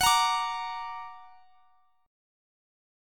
Listen to F#+ strummed